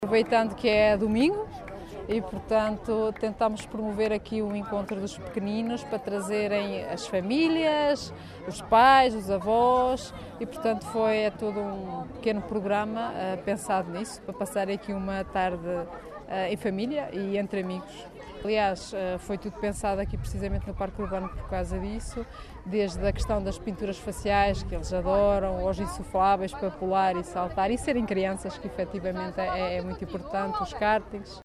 A iniciativa partiu da câmara municipal de Macedo de Cavaleiros, para proporcionar, sobretudo um dia em família, explica a vereadora com o pelouro da educação, Sónia Salomé: